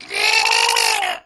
Goblin_Death2.wav